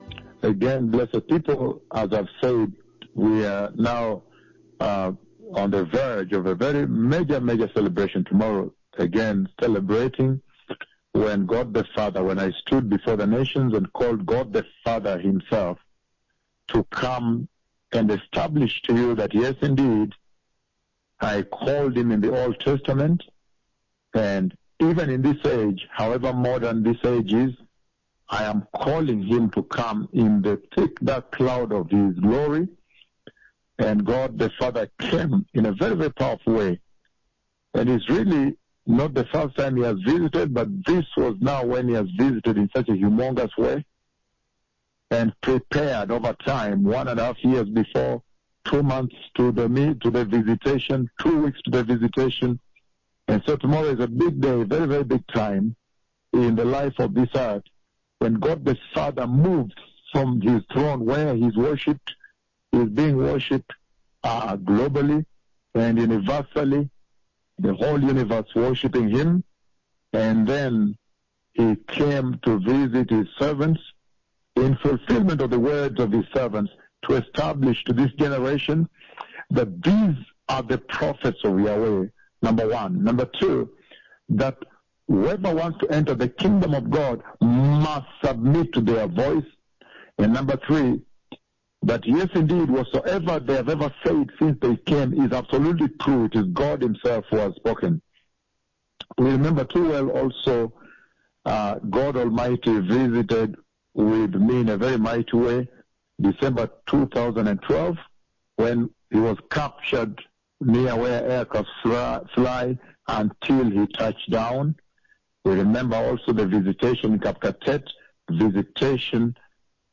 PRÉSENTÉE PAR LES DEUX MÉGA PUISSANTS PROPHÈTES DE JÉHOVAH.
Diffusion capturée depuis JESUS IS LORD RADIO